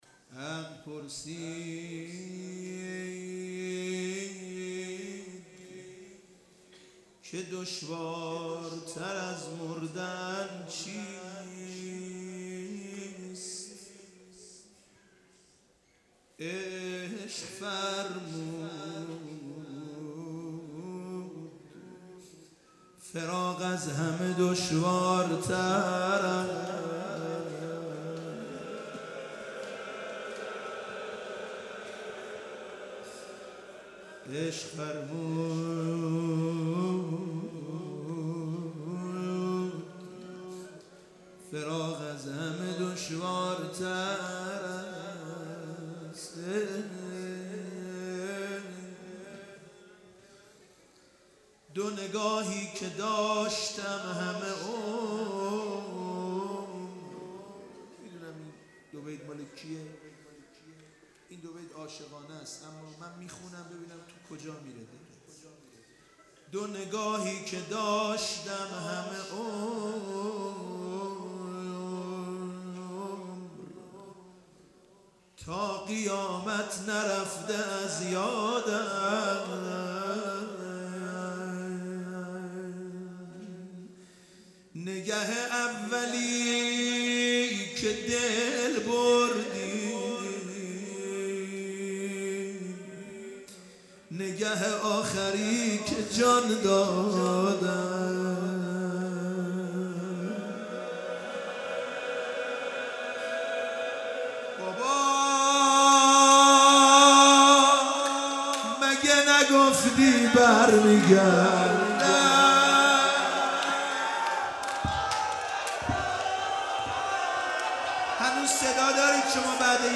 9  روضه حضرت رقیه سلام الله علیها (مناجات)